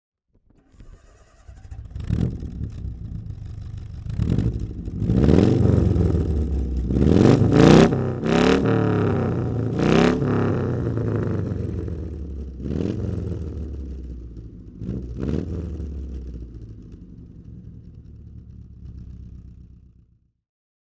Marcos 2 Litre V4 (1970) - Starten und Leerlauf